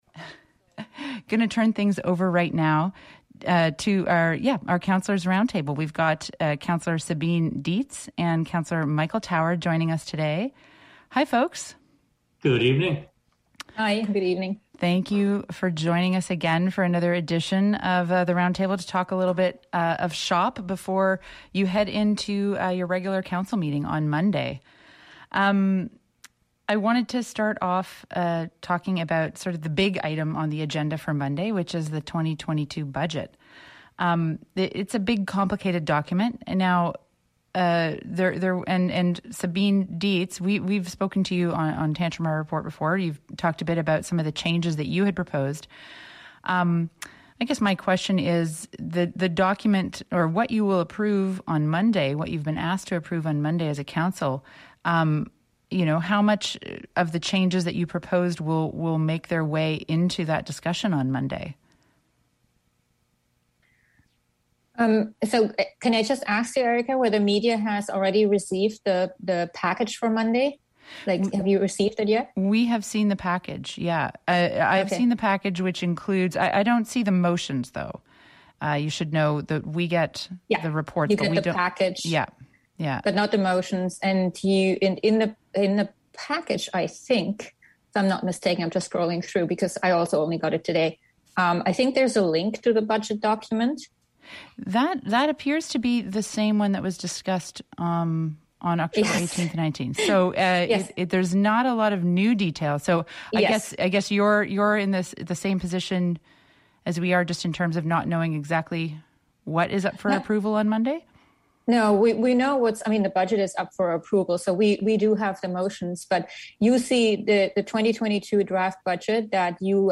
Another edition of the monthly CHMA Talks councillors roundtable with Sabine Dietz and Michael Tower, talking about the 2022 budget, funding health care recruitment, and the potential for a future multipurpose building for Sackville.